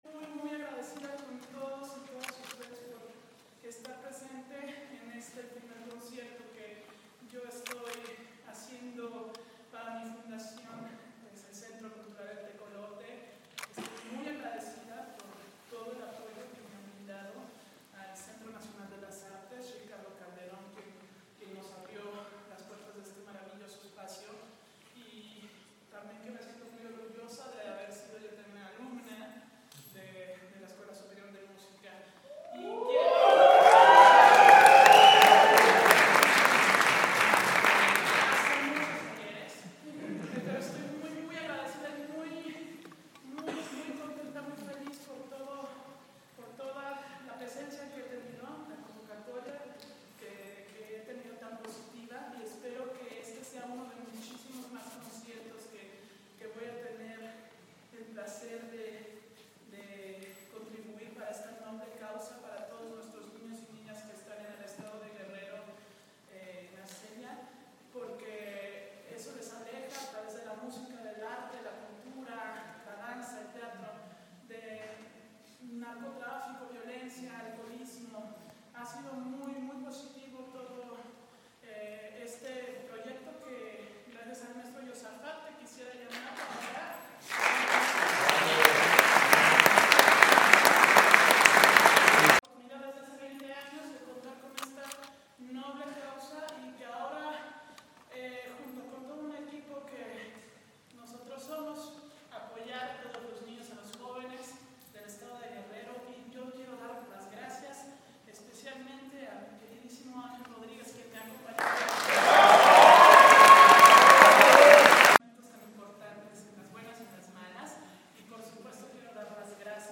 ¡Bravo! exclamó el público al ovacionar de pie a la soprano mexicana María Katzarava luego del recital que ofreció.